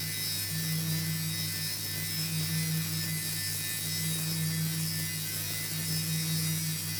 Weapon 08 Loop (Laser).wav